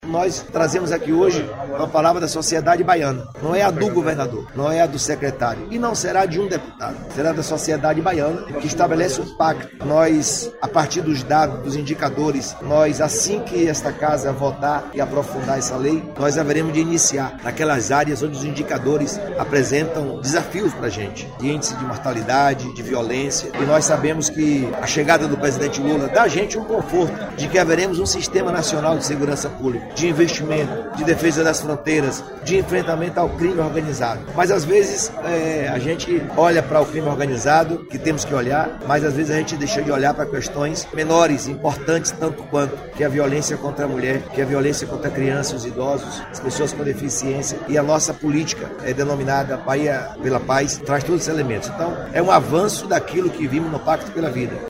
🎙Jerônimo Rodrigues, Govenador da Bahia
A entrega do projeto de lei foi feita pelo governador Jerônimo Rodrigues, em ato público na Assembleia Legislativa da Bahia.